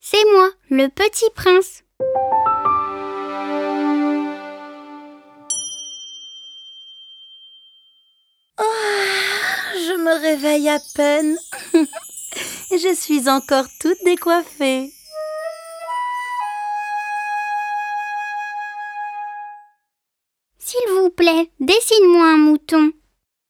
Grand imagier sonore de l’histoire du Petit Prince reprenant les phrases cultes de l’histoire. Proposition du casting, direction des comédiens et mise en musique du texte.